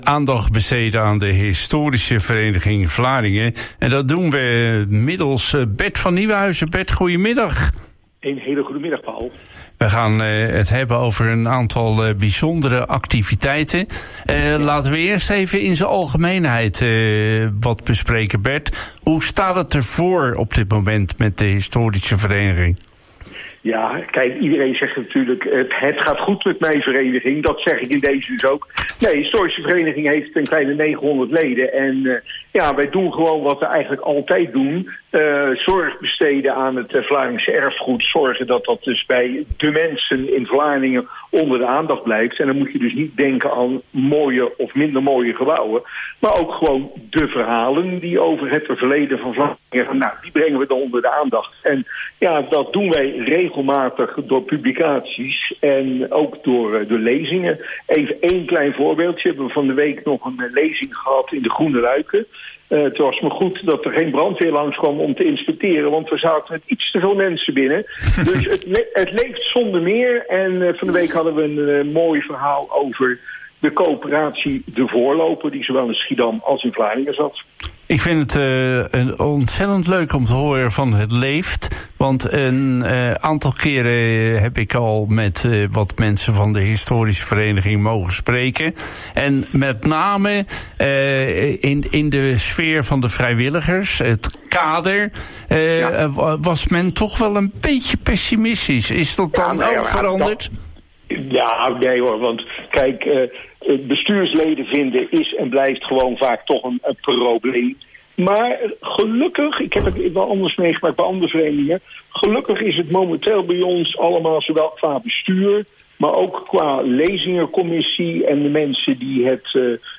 Een interview mede in verband met de uitreiking van ons Jaarboek 2024 op dinsdag 3 december vanaf 16.00 uur in Kade40.